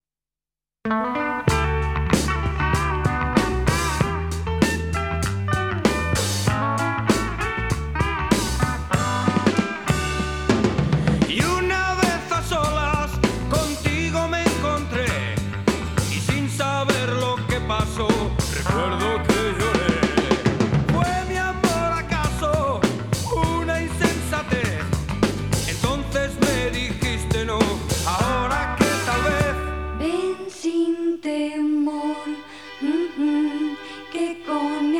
Жанр: Рок
# Rock & Roll